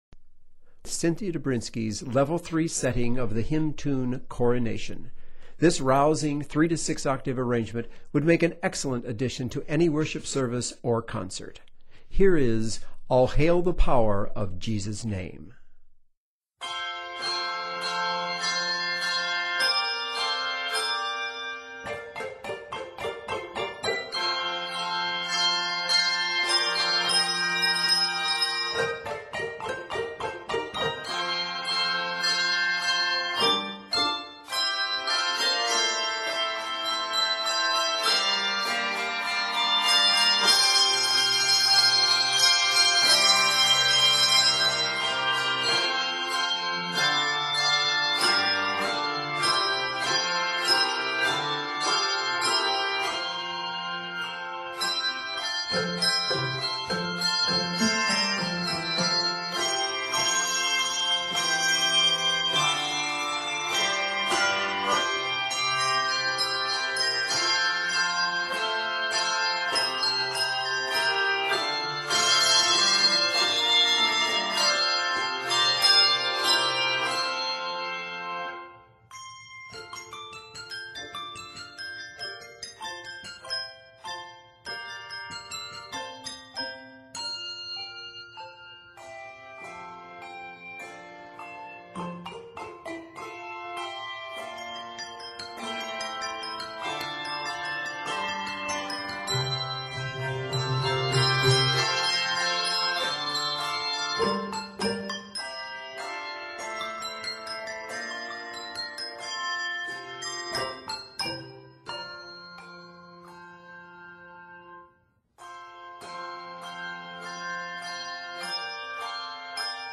It is scored in F Major.